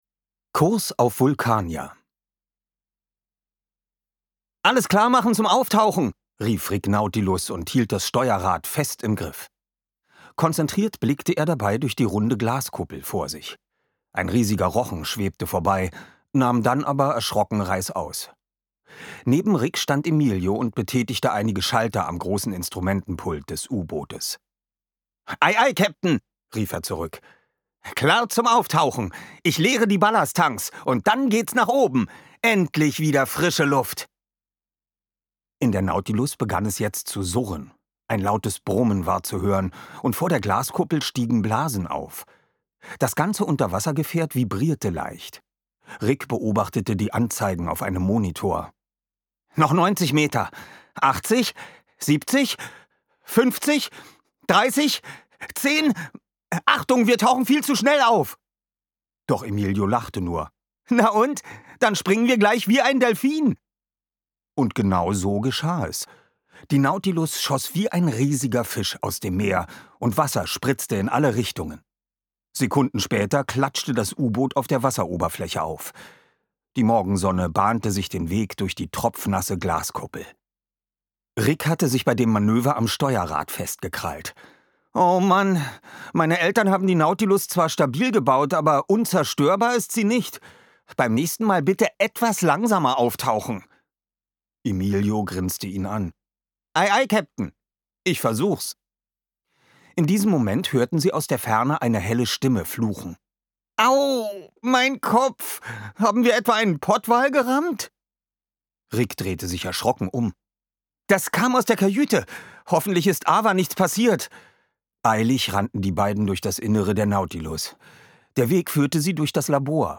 Rick Nautilus – Teil 2: Gefangen auf der Eiseninsel Ungekürzte Lesung mit Musik mit Oliver Rohrbeck
Oliver Rohrbeck (Sprecher)